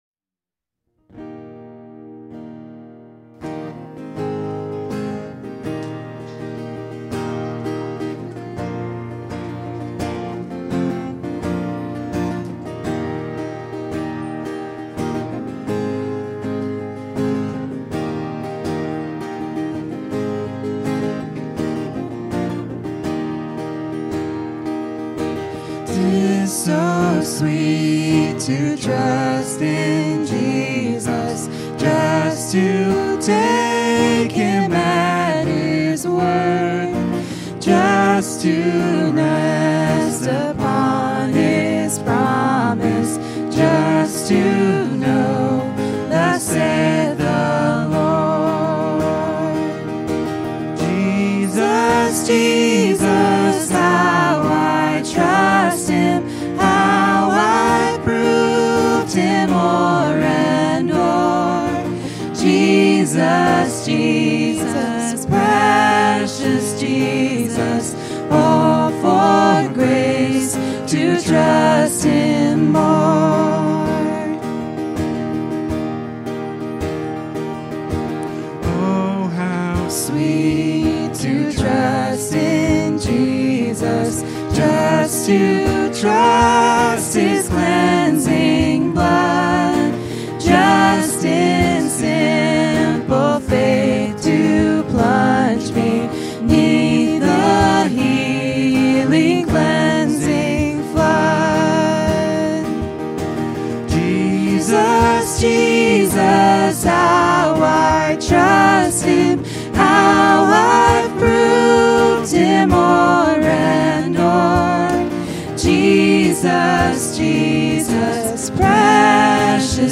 Worship 2025-11-09